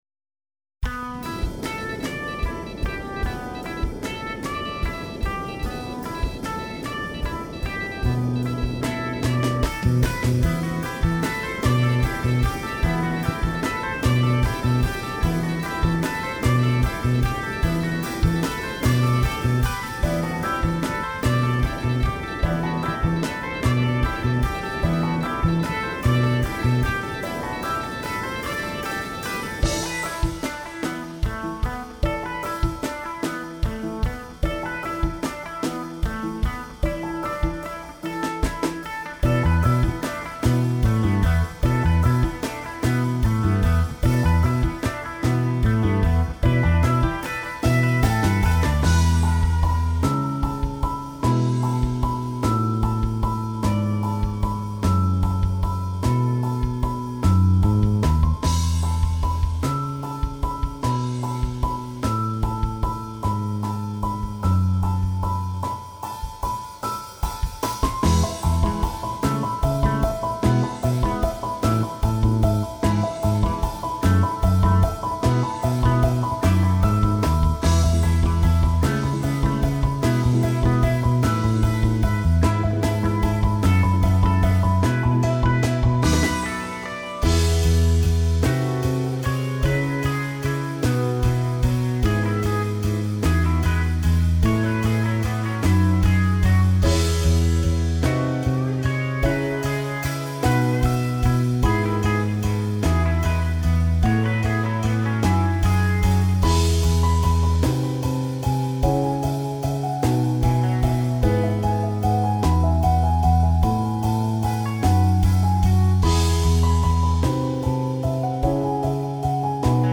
インストゥルメンタルニューエイジロング
BGM